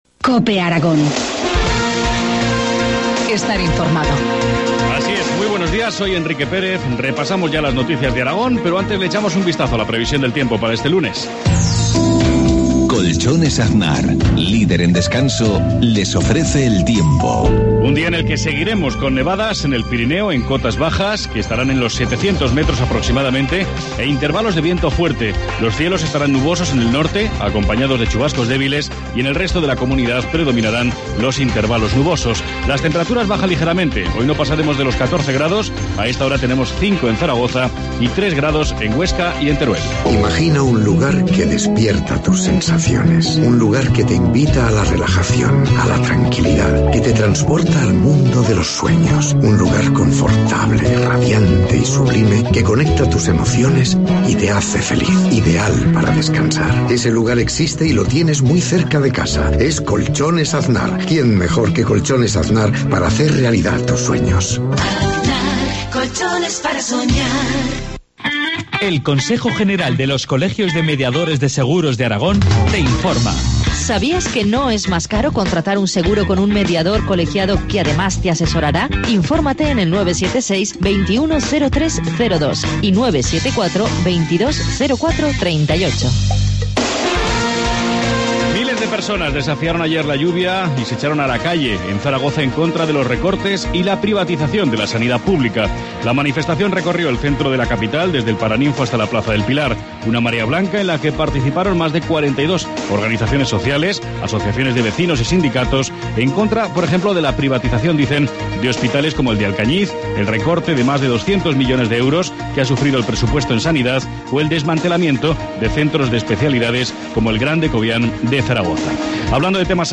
Informativo matinal, lunes 18 de marzo, 7.53 horas